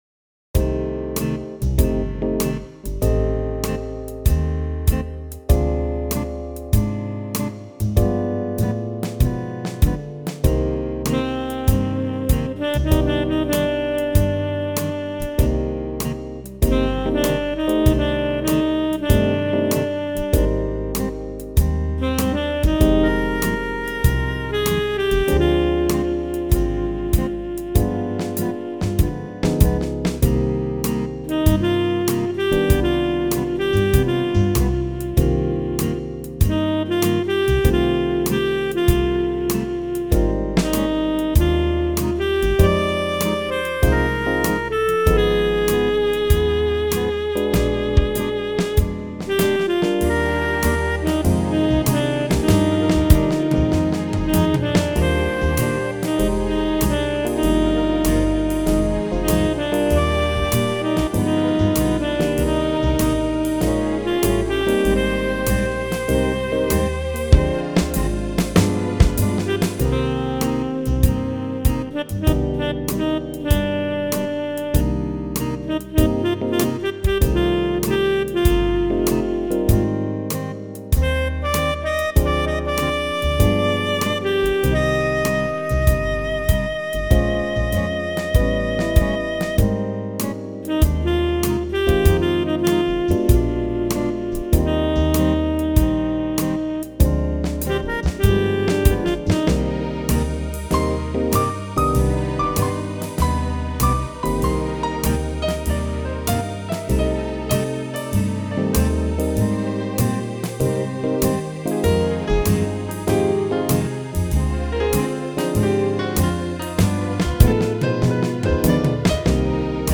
It’s on the Jazz/Swing list.